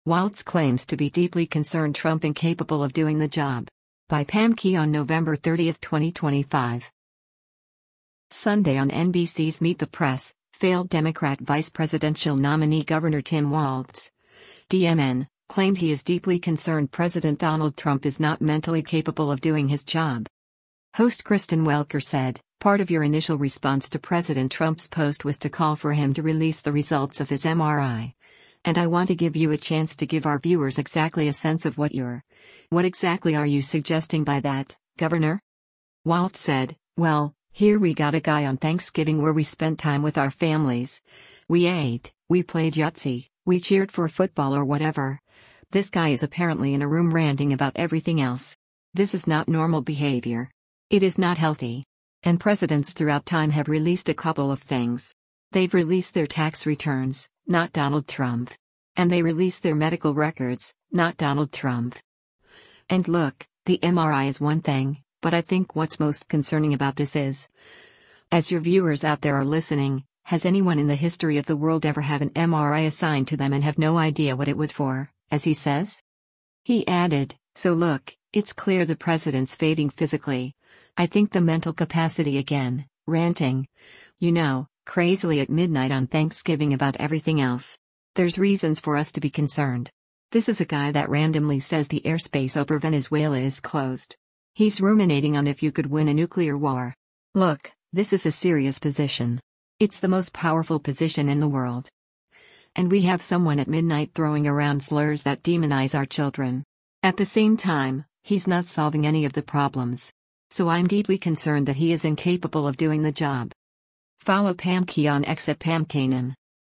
Sunday on NBC’s “Meet the Press,” failed Democrat vice-presidential nominee Gov. Tim Walz (D-MN) claimed he is “deeply concerned” President Donald Trump is not mentally capable of doing his job.